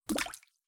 water_splash.mp3